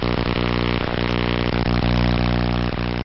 Pretty Blood Electric Shock 2 Sound Effect Download: Instant Soundboard Button